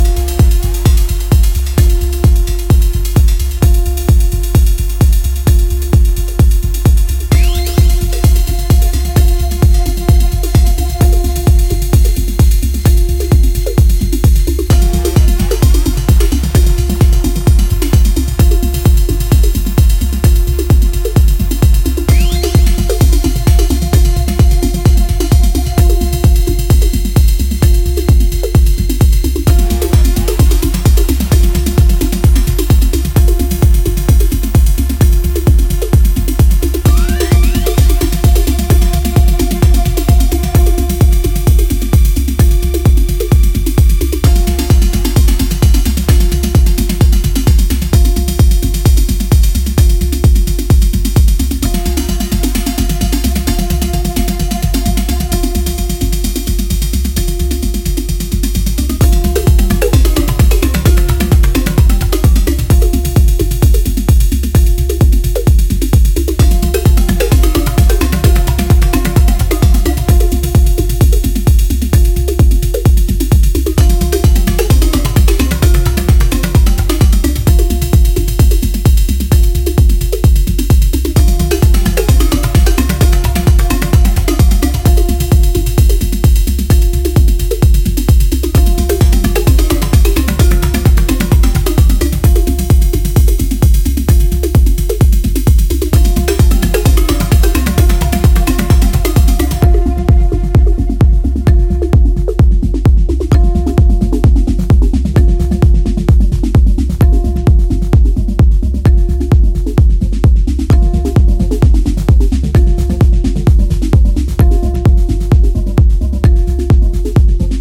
electronic music
darker and more intense with heady loops and wispy pads